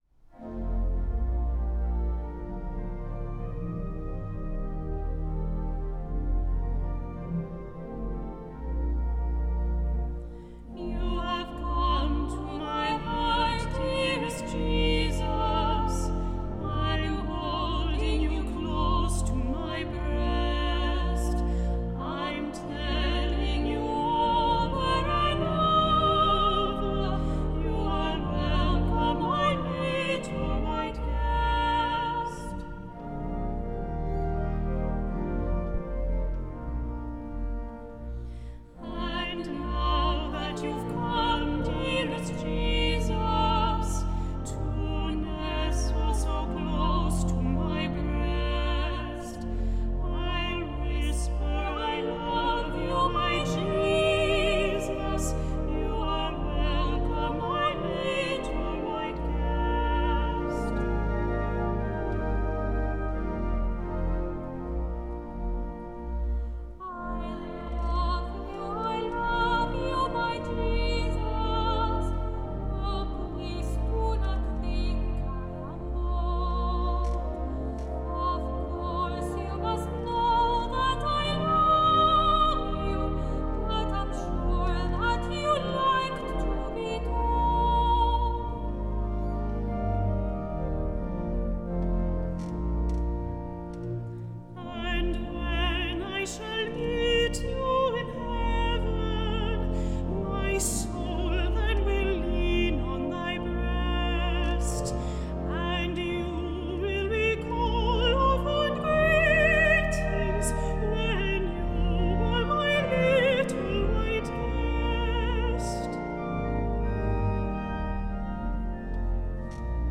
Recorded at St. Paul’s R.C. Church, Cambridge, MA, August 2014